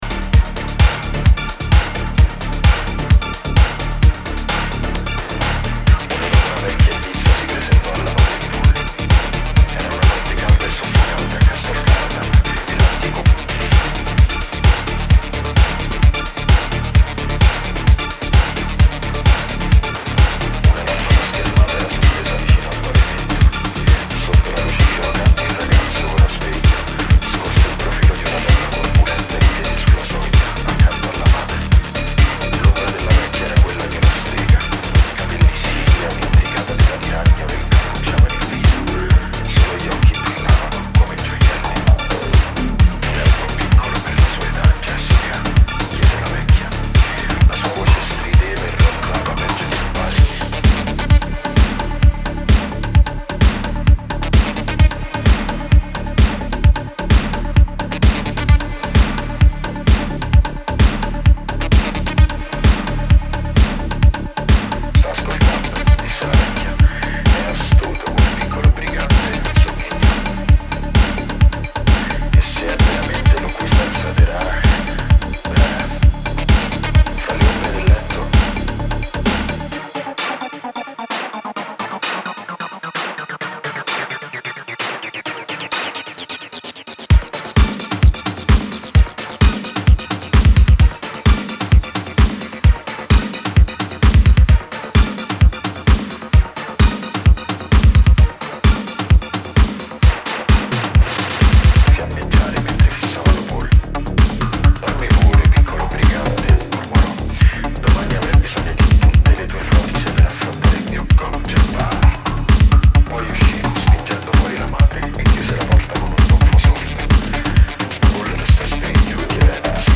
dark flavour